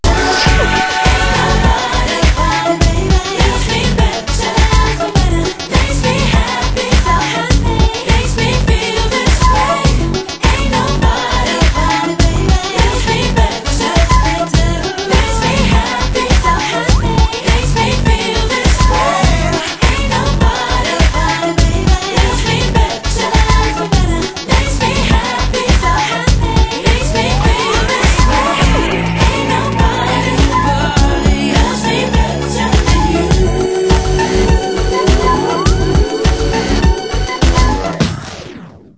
on another futuristic slice of pop-dance bootie action.
funky electro-pop backdrop